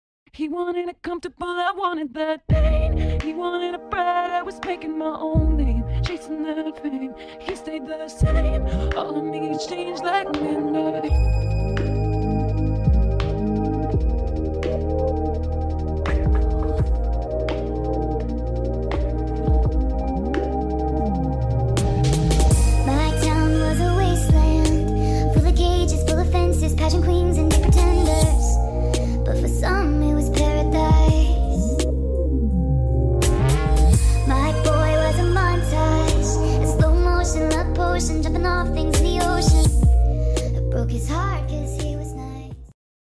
Sped Up!